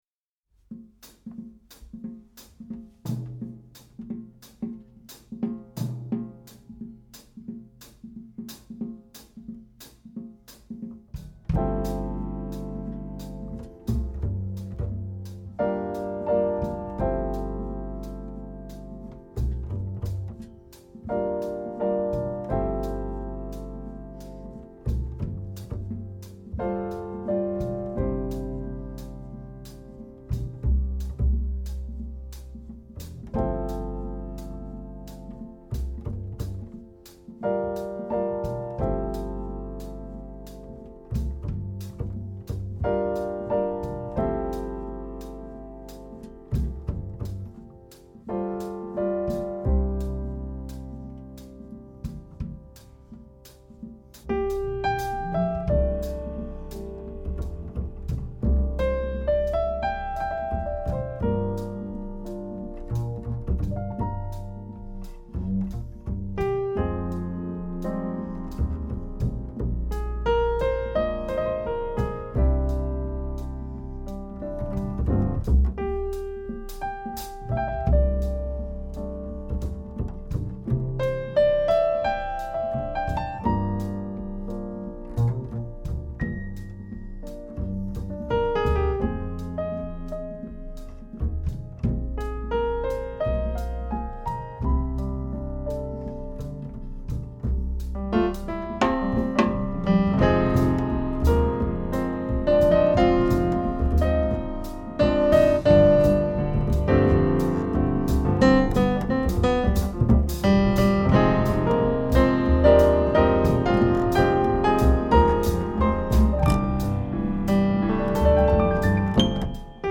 ★ 匯集多種音樂元素，中國優秀爵士音樂家鼎力相助，再現正宗古巴爵士樂風音樂！
烘托爵士現場的微妙氛圍。